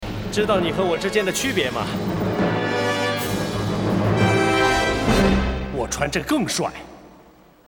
The same guys did Mandarin dubs for all of the Lethal Weapon series.